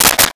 Switch 06
Switch 06.wav